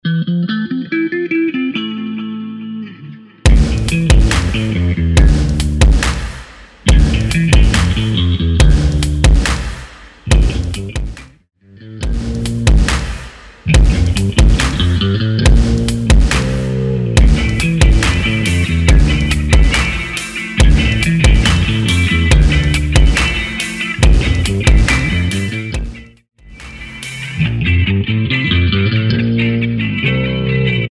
Music Beds
Mellow Rock